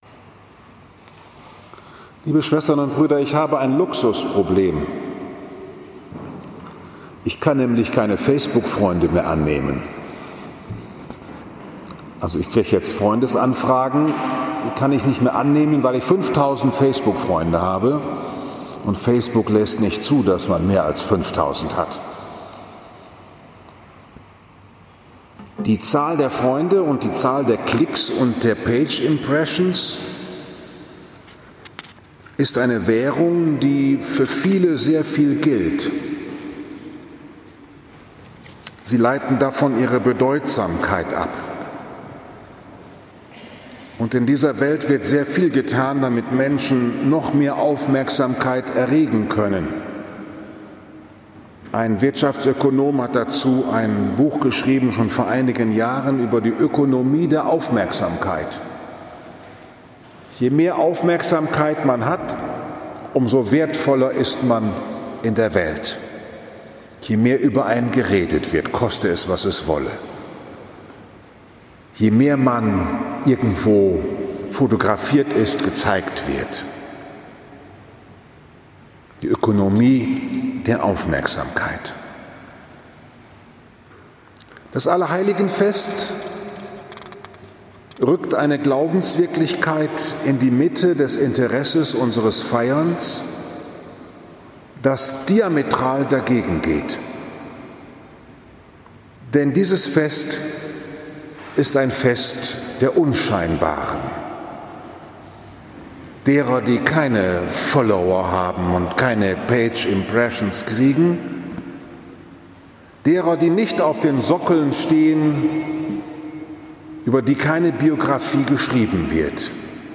Selig die Unscheinbaren ~ Bruder Paulus´ Kapuzinerpredigt Podcast
Selig die Unscheinbaren Die Wichtigsten in der Kirche sind jene, die keine Schlagzeilen oder Klicks erhalten; sie verwandeln die Welt weltweit 1. November 2020, 11 Uhr Liebfrauenkirche Frankfurt am Main, Allerheiligen